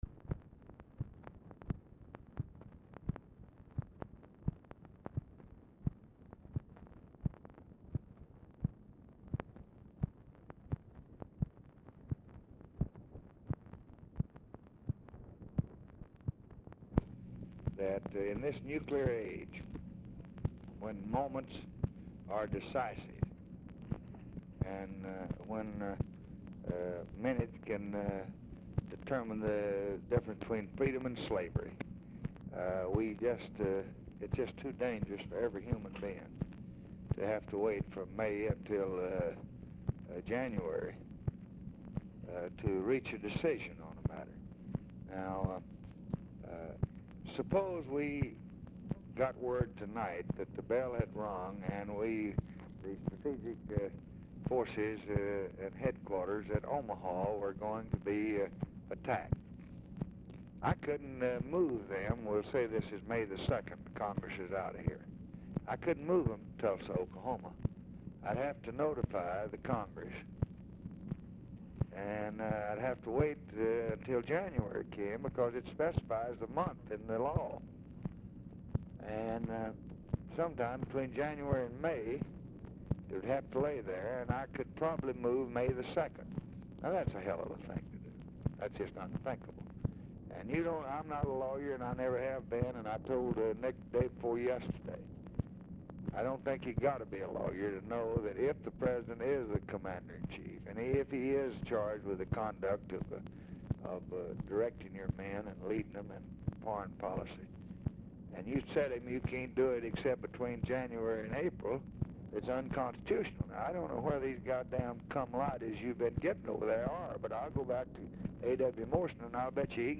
Telephone conversation # 8584, sound recording, LBJ and RAMSEY CLARK, 8/20/1965, 9:50PM
RECORDING STARTS AFTER CONVERSATION HAS BEGUN
Format Dictation belt